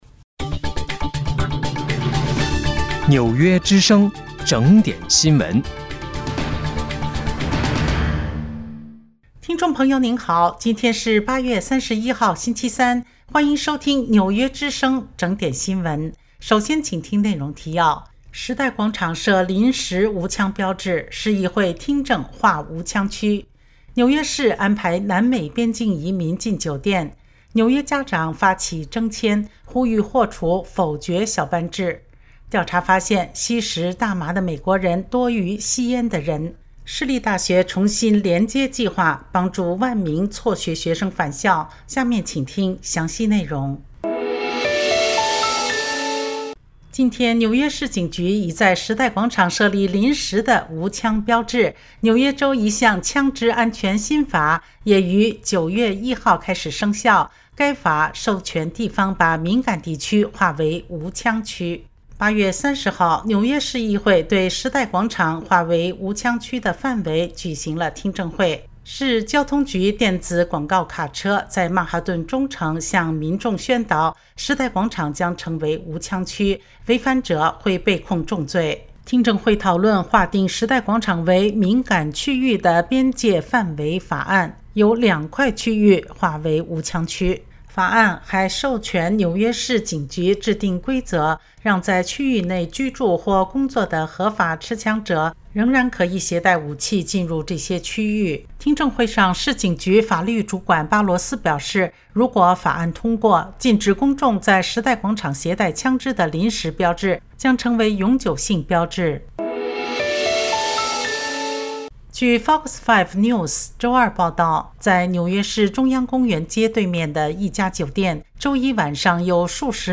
8月31日（星期三）纽约整点新闻